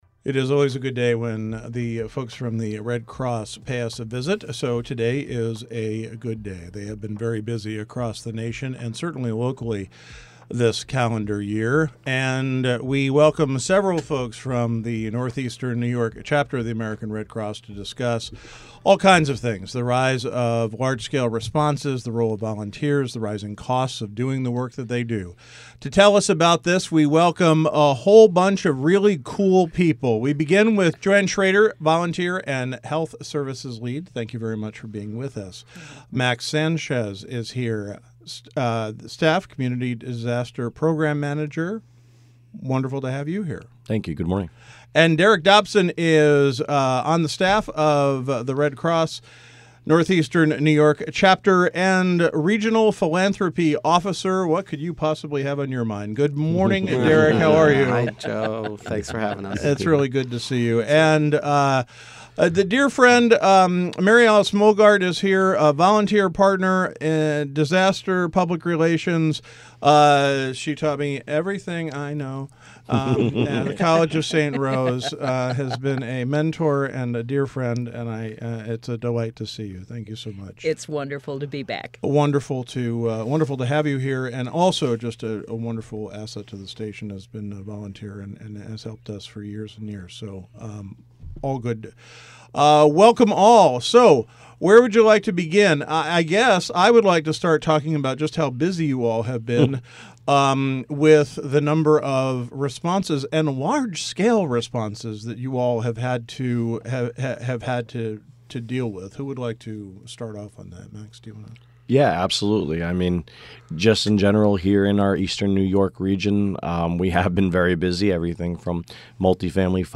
WAMC's The Roundtable is an award-winning, nationally recognized eclectic talk program.